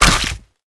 PixelPerfectionCE/assets/minecraft/sounds/mob/skeleton/hurt3.ogg at mc116
hurt3.ogg